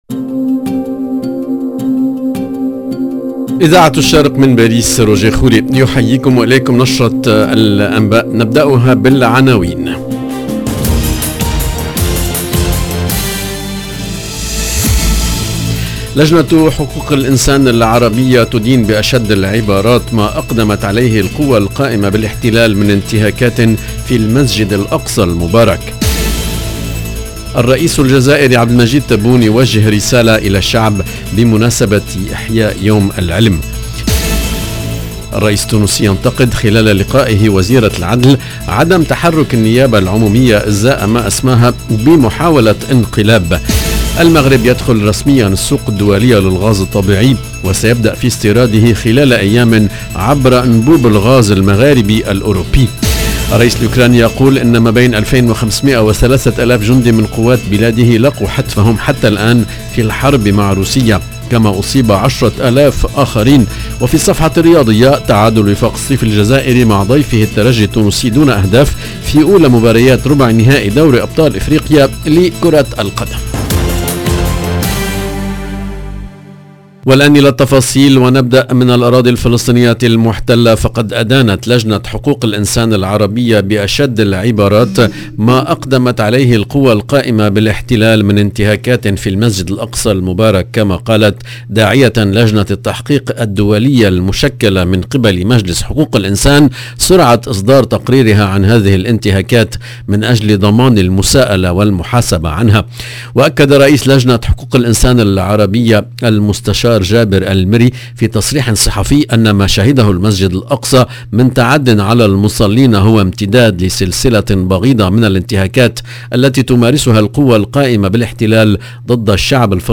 LE JOURNAL DE MIDI 30 EN LANGUE ARABE DU 16/04/22